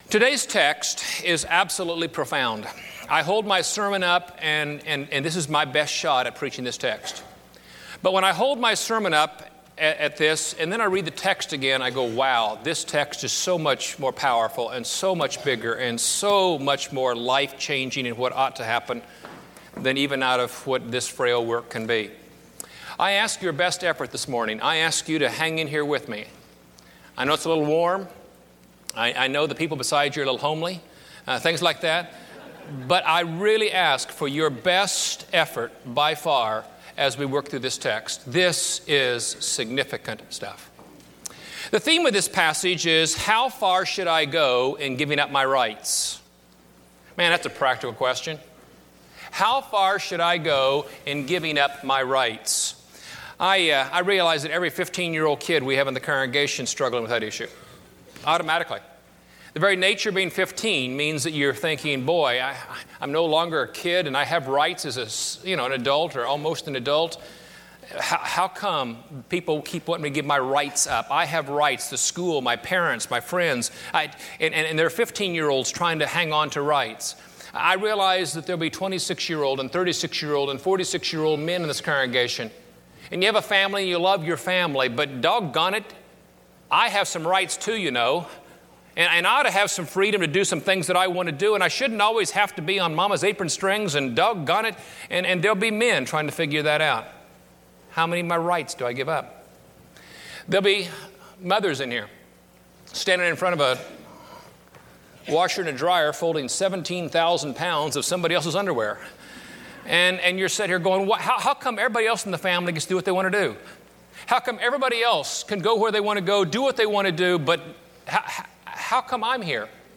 Giving Up Your Freedom Preached at College Heights Christian Church October 9, 2005 Series: 1 Corinthians 2005 Scripture: 1 Corinthians 9 Audio